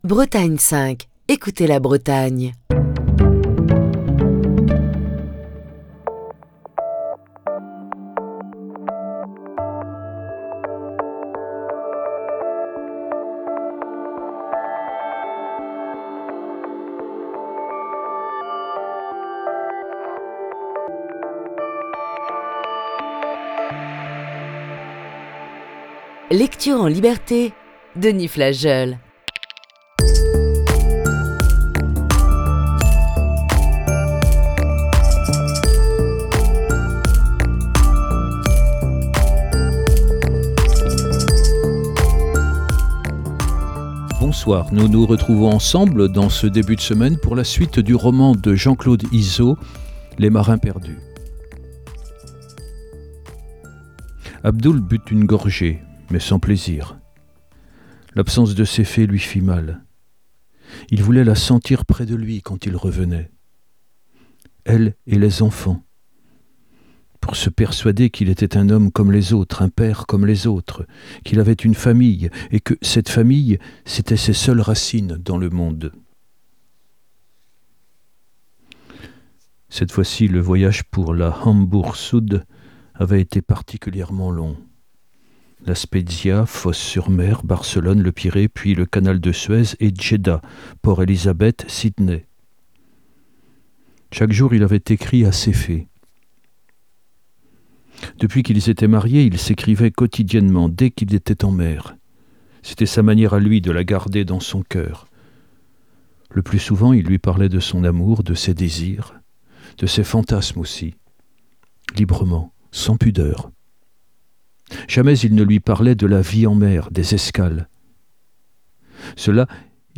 Ce lundi voici la sixième partie de ce récit.